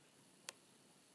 Random clicking / popping noise from 2018 Mac Mini